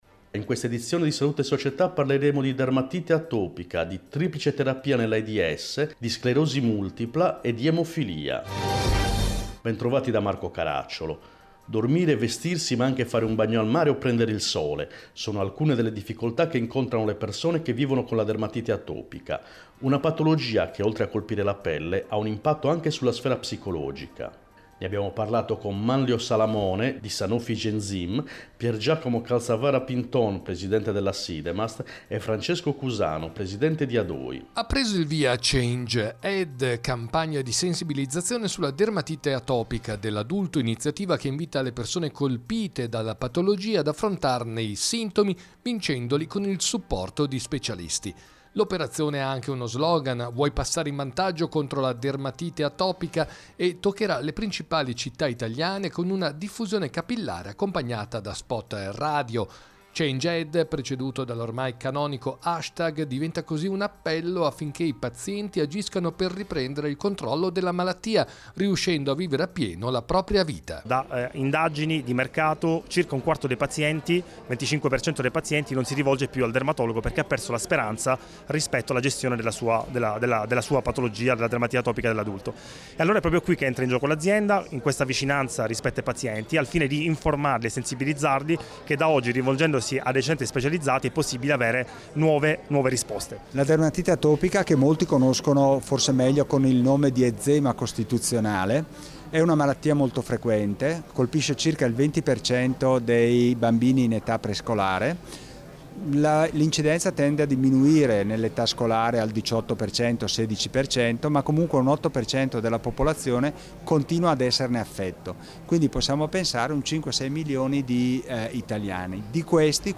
In questa edizione: 1. Dermatologia, Dermatite atopica 2. HIV/AIDS, Triplice terapia 3. Sclerosi multipla, Tecnologia digitale 4. Emofilia, A fianco del coraggio Interviste